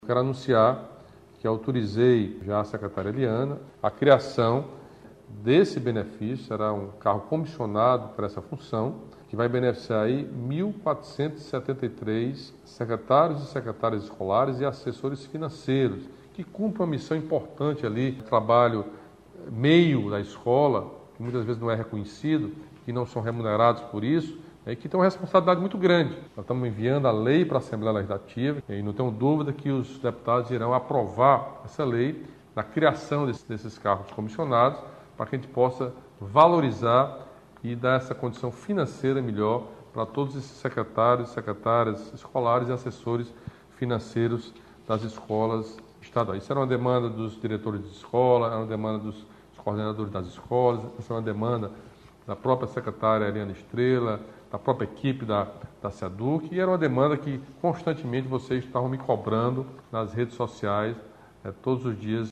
Nesta terça-feira (25), durante seu tradicional bate-papo com a população pelas redes sociais, o governador Camilo Santana comunicou o reajuste aos agentes rurais da Ematerce e a criação de uma gratificação para assessores financeiros e secretários escolares da Seduc.